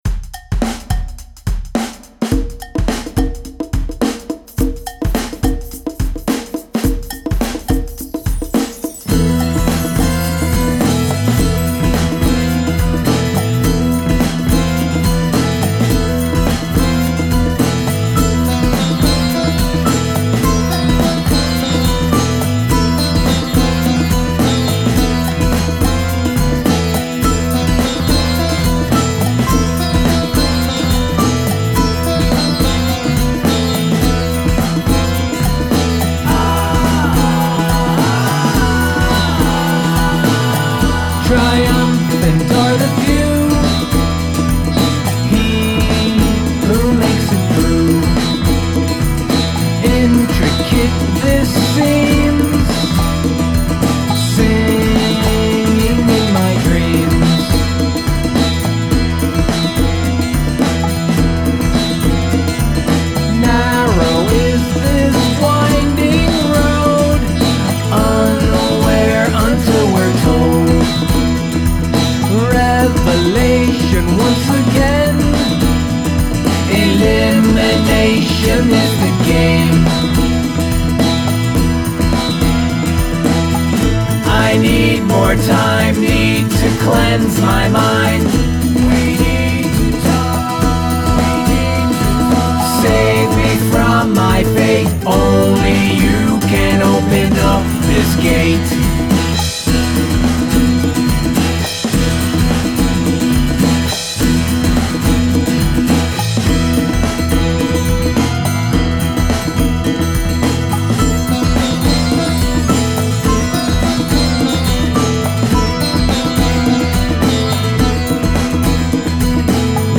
Sitar licks and guitar call and response is fantastic!